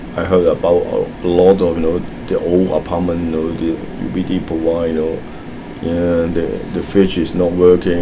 S1 = Taiwanese female S2 = Hong Kong male Context: S2 is talking about why he moved into the apartment he currently lives in.
The main problems with provide are the absence of [r] and also the use of [w] instead of [v] as the medial consonant.